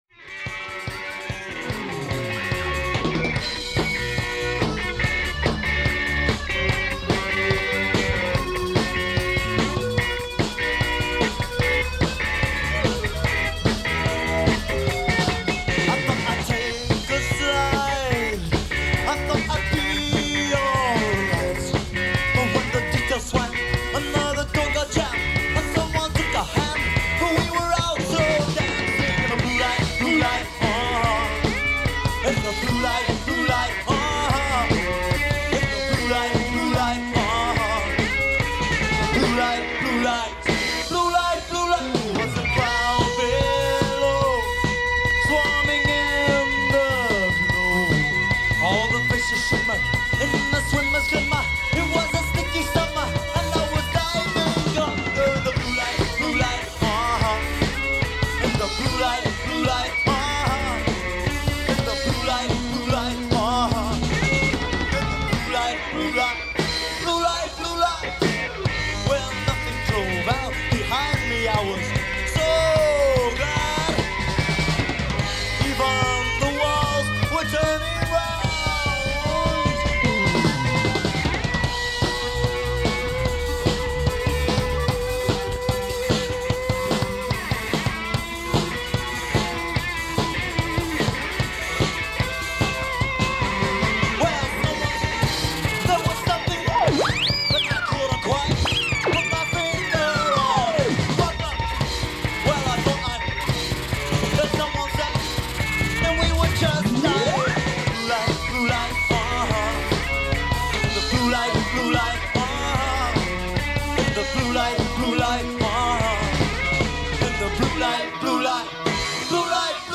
new wave band